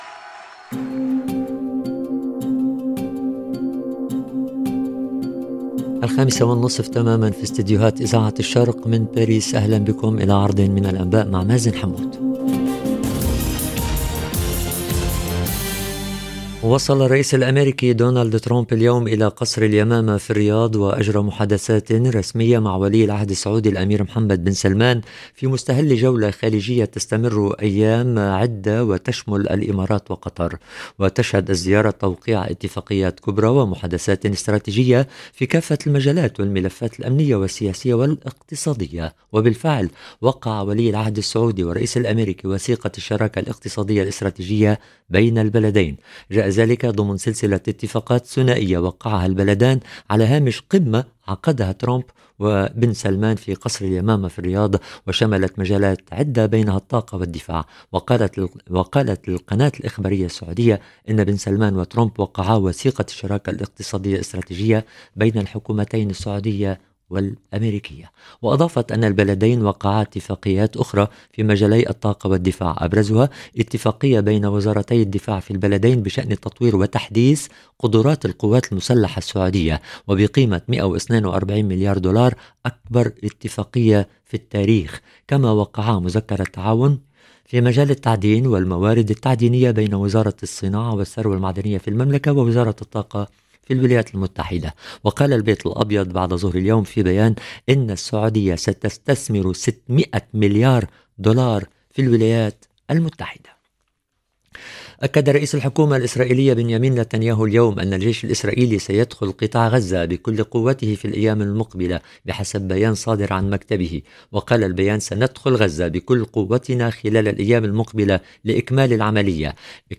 LE JOURNAL EN LANGUE ARABE DU SOIR 13/05/2025